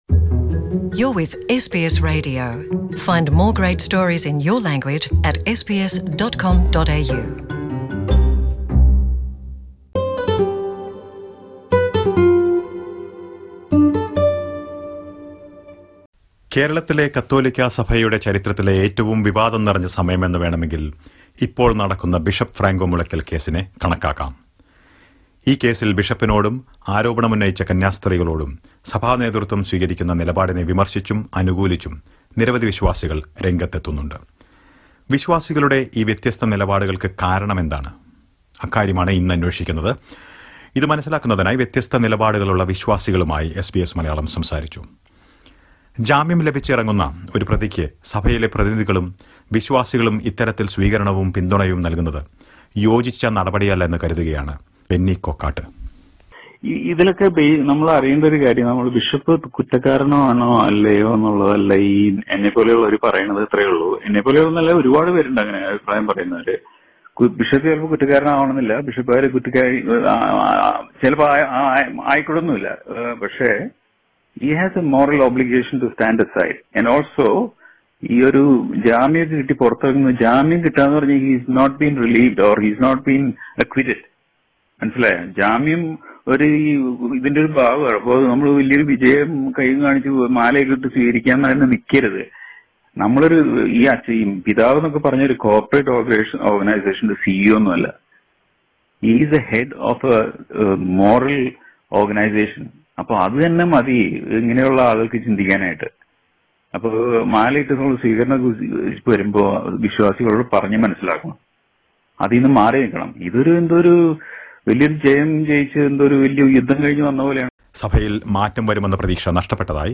Bishop Franco Mullakal case in India has the believers divided in their opinion about the way in which the church leadership has handled the matter so far. SBS Malayalam speaks to a few believers who share their views.